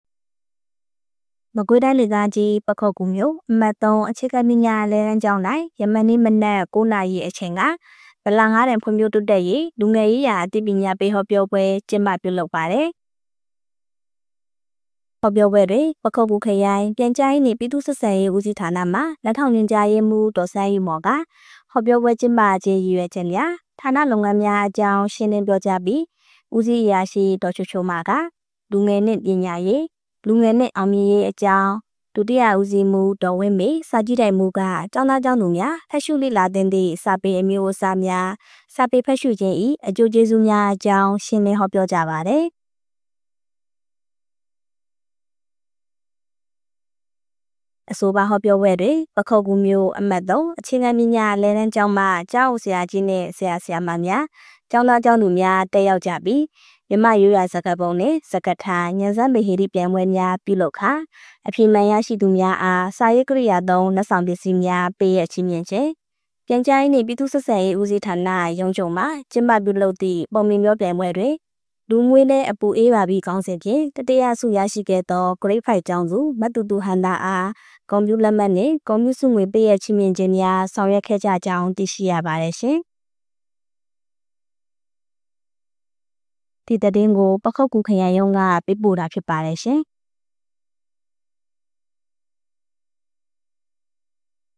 ပခုက္ကူမြို့၌ ဗလငါးတန်ဖွံ့ဖြိုးတိုးတက်ရေး လူငယ်ရေးရာအသိပညာပေးဟောပြောပွဲပြုလုပ် ပခုက္ကူ။ ဩဂုတ် ၇